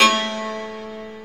SI2 PIANO05R.wav